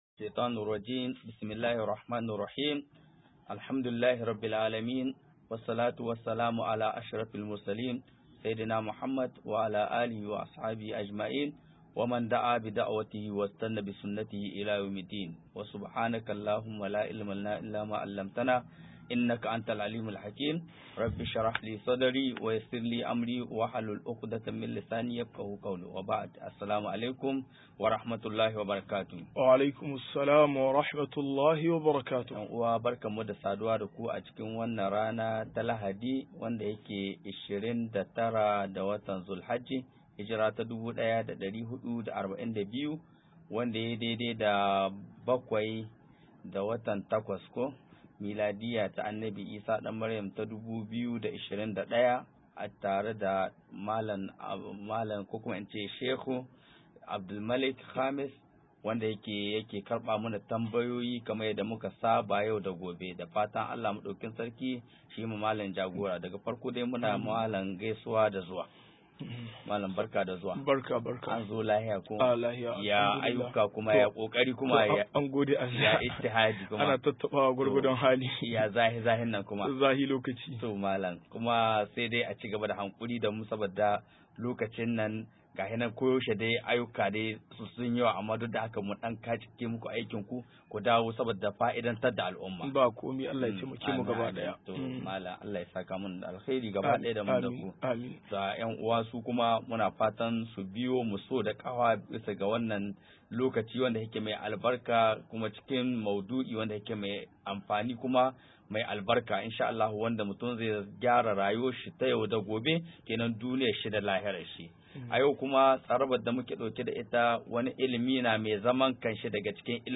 173-Ka i dodin Fikihu - MUHADARA